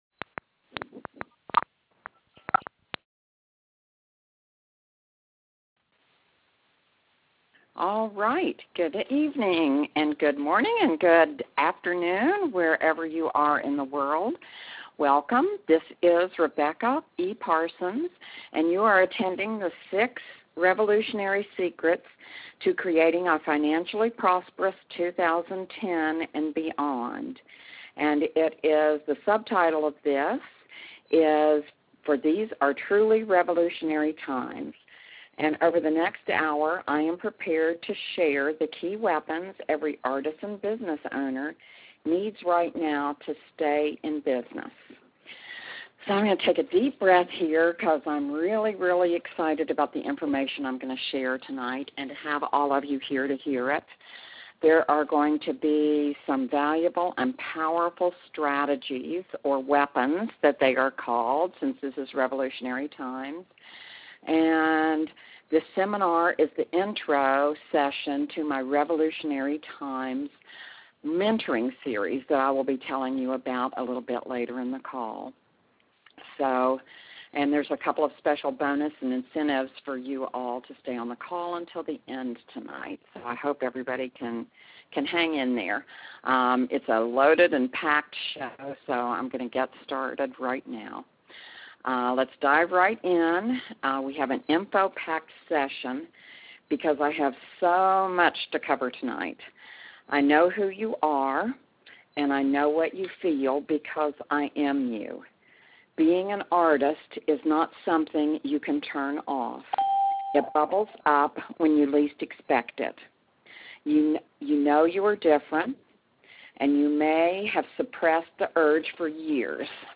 Click to download: Revolutionary Times Teleseminar
This is the replay of the Revolutionary Times Teleseminar from March 22, 2010.